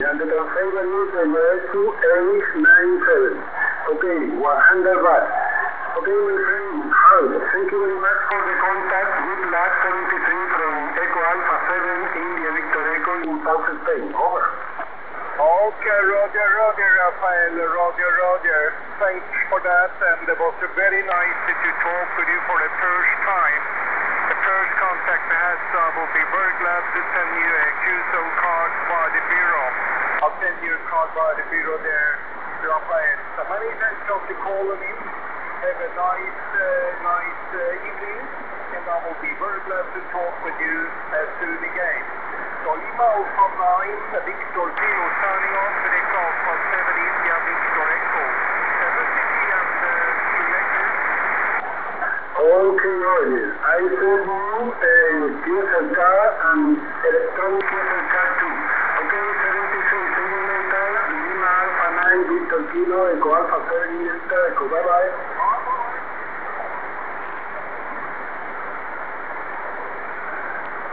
Ci-dessous deux enregistrements de conversation HF pendant lesquels j'ai réalisé des coupures à courir du filtre DSP. Vous n'aurez pas de mal à repérer les périodes où le filtre est actif vu que le bruit de fond diminue fortement :
Test sur 7 MHz
test-dsp-7mhz.wav